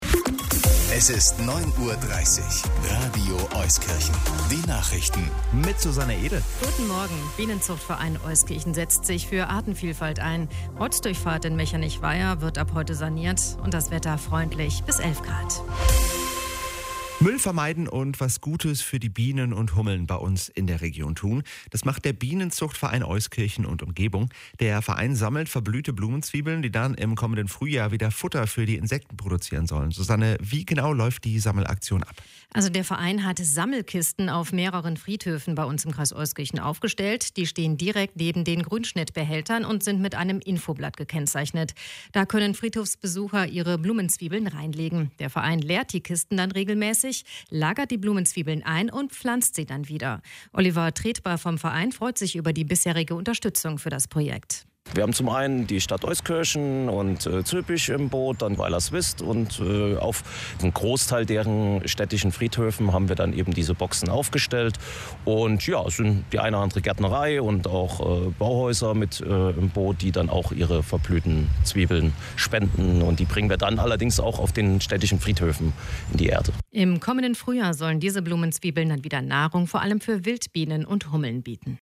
26.04.2023_Radio Euskirchen Bericht
News_Blumenzwiebelrettung.mp3